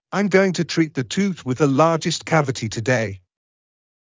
ｱｲﾑ ｺﾞｰｲﾝｸﾞ ﾄｩｰ ﾄﾘｰﾄ ｻﾞ ﾄｩｰｽ ｳｨｽﾞ ｻﾞ ﾗｰｼﾞｪｽﾄ ｷｬﾋﾞﾃｨ ﾄｩﾃﾞｲ